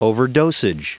Prononciation du mot overdosage en anglais (fichier audio)
Prononciation du mot : overdosage